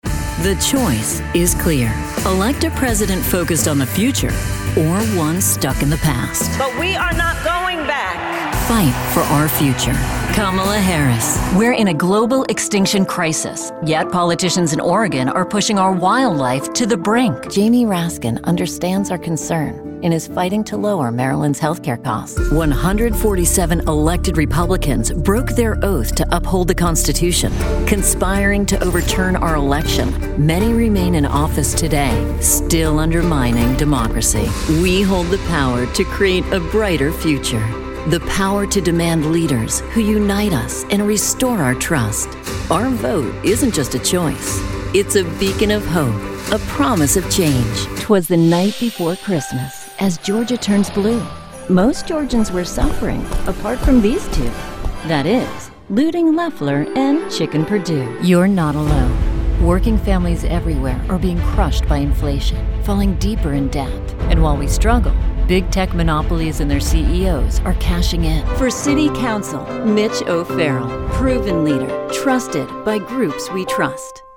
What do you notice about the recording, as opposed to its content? Vocalbooth which is housed in a sound treated room (for extra peach of mind :) )